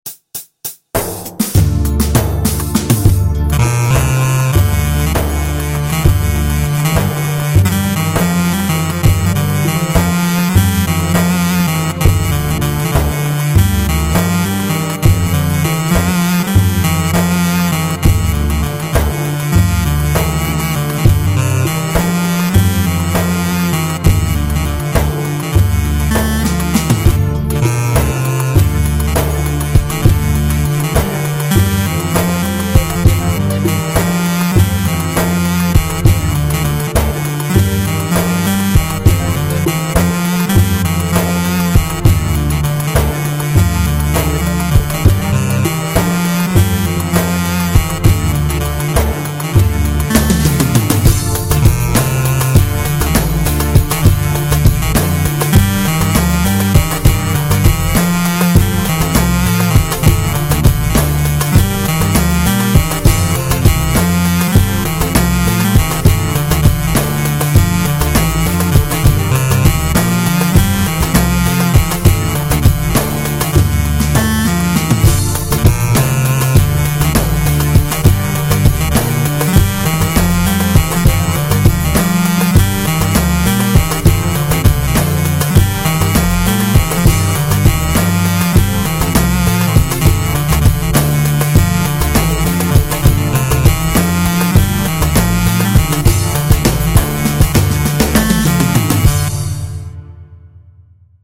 Roland Bk5 Or -Kınayı Getir ( Mey ) - Demo